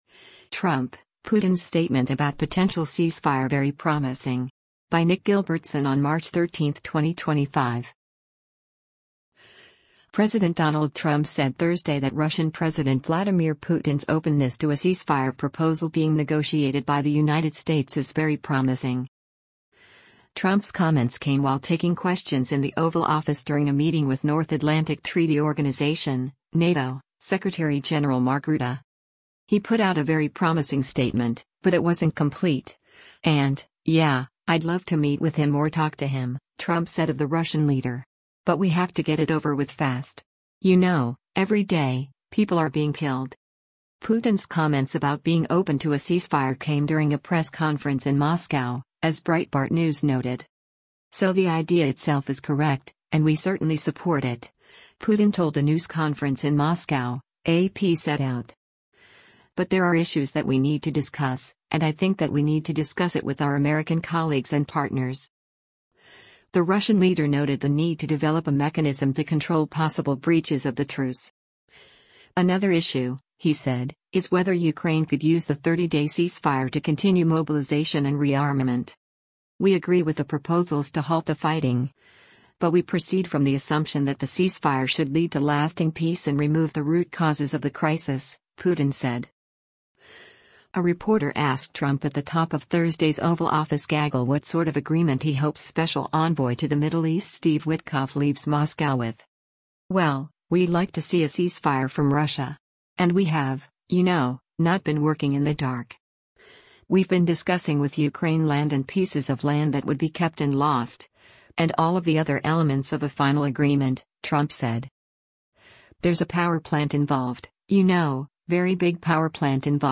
Trump’s comments came while taking questions in the Oval Office during a meeting with North Atlantic Treaty Organization (NATO) Secretary General Mark Rutte.